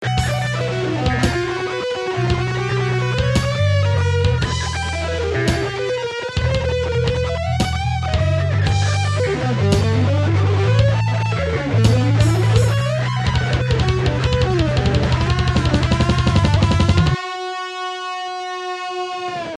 ������ ����, presonus inspire